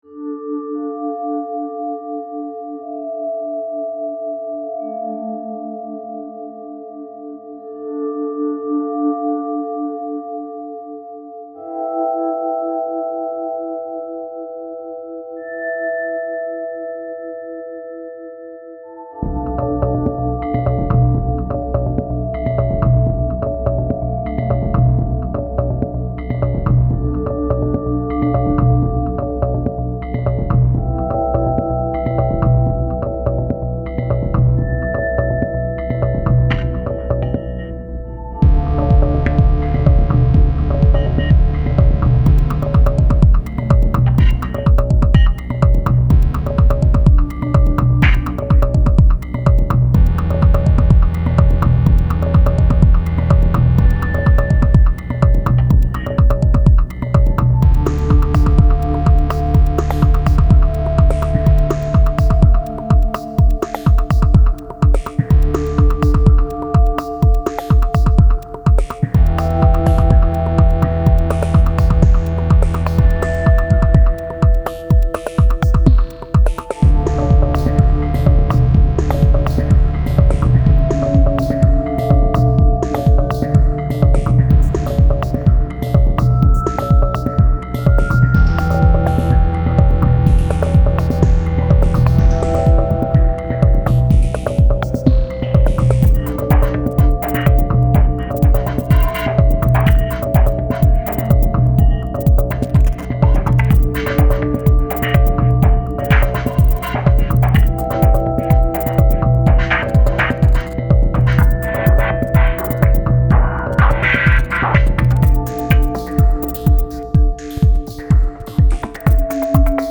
downbeat style